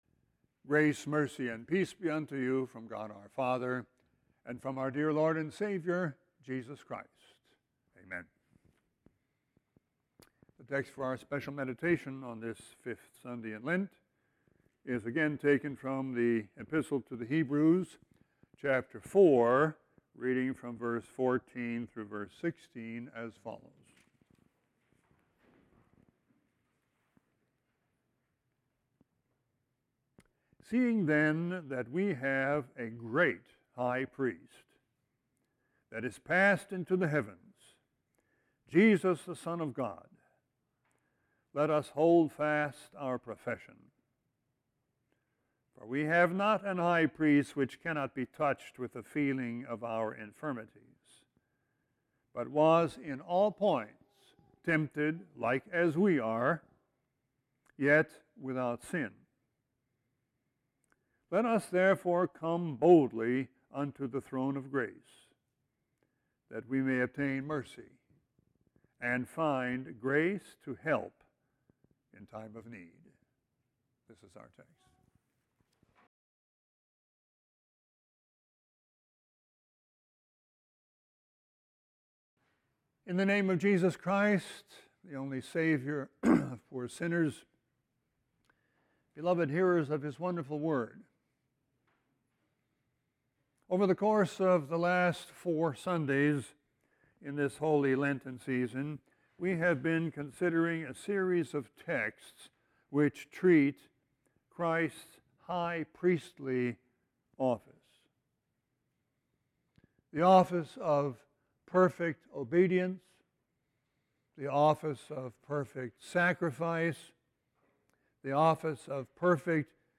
Sermon 3-18-18.mp3